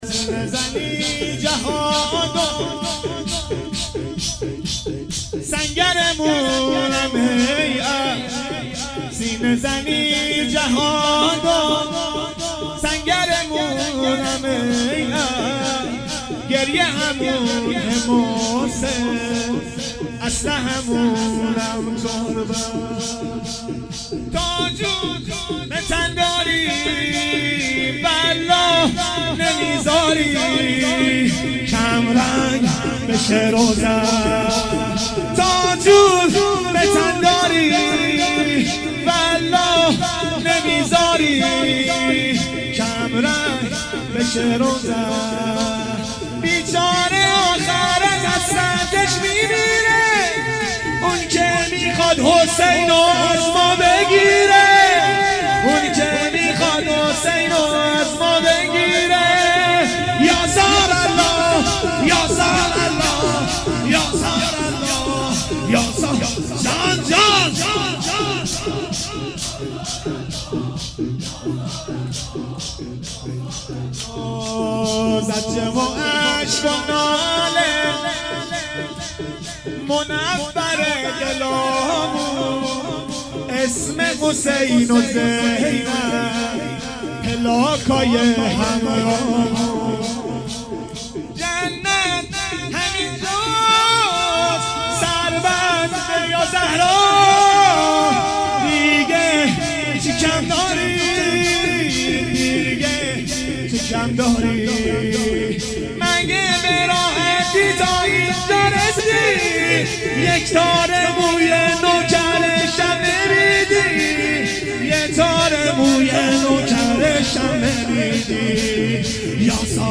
جلسه هفتگی 22-11-93حسینیه ی سپهسالار کربلا
روضه حضرت زهرا (س)
سینه زنی